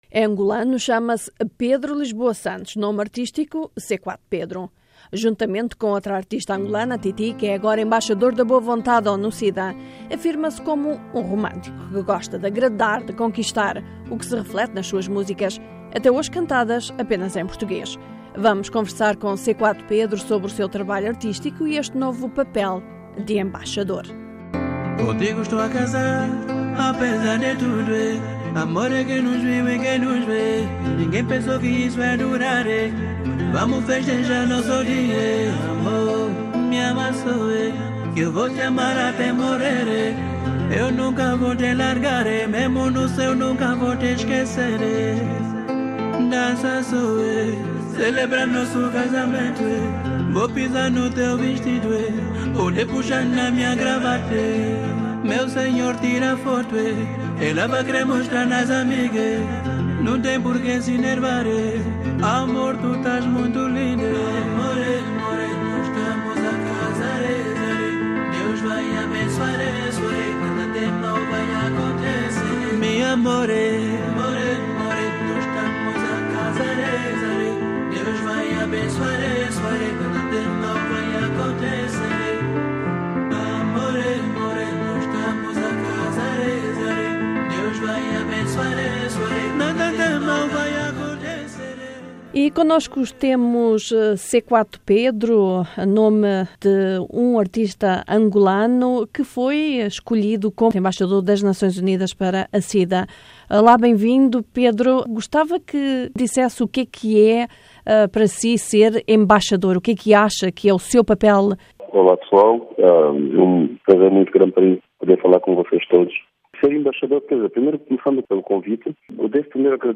Entrevista com cantor angolano C4 Pedro